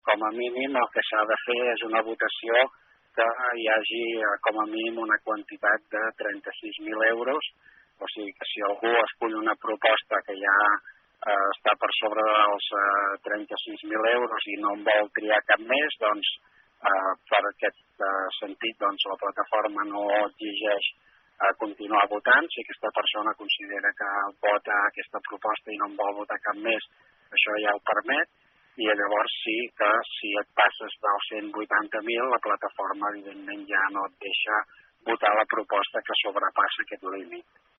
En declaracions a aquesta emissora, el regidor de Participació Ciutadana de Malgrat, Ramir Roger, explica que la resposta de la ciutadania ha estat positiva, i posa com a exemple les demandes que han rebut durant els dos dies de retard.